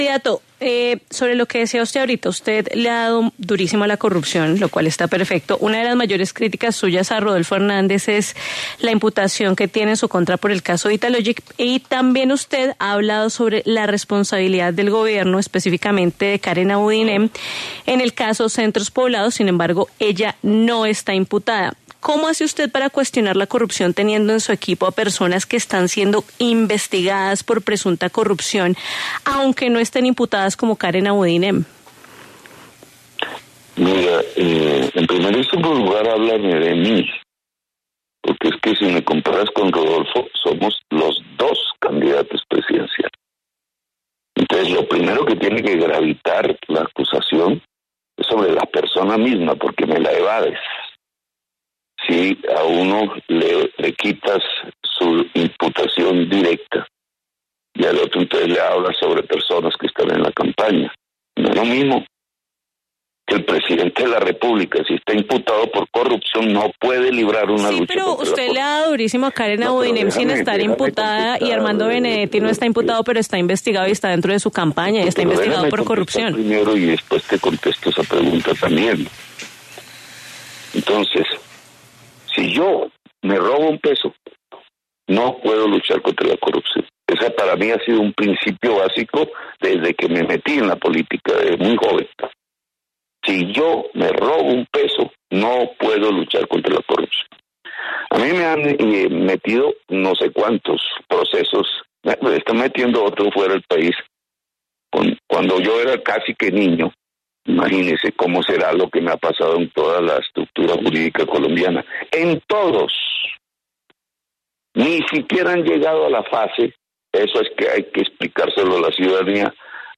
En entrevista con La W, con Julio Sánchez Cristo, el candidato presidencial del Pacto Histórico, Gustavo Petro, habló sobre los resultados de las votaciones de primera vuelta y los retos que tiene de cara a la segunda vuelta presidencial.
Gustavo Petro habla en La W de cara a la segunda vuelta electoral